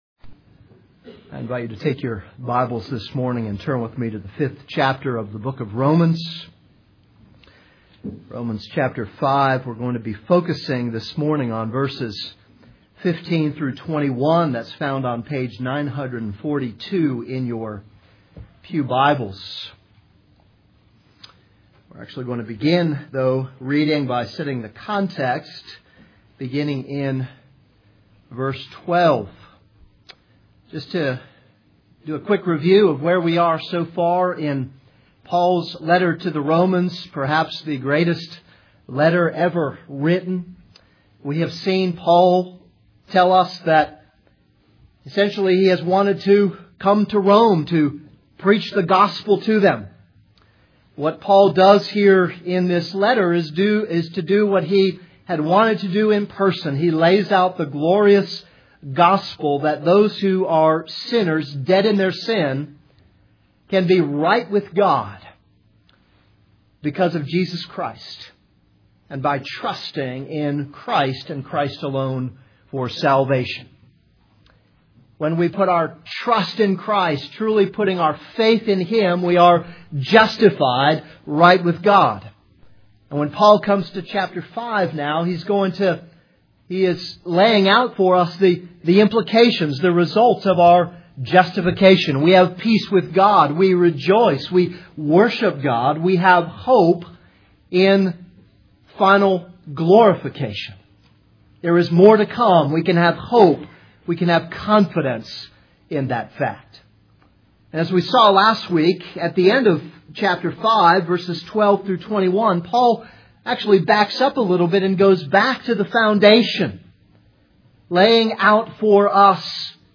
This is a sermon on Romans 5:15-21.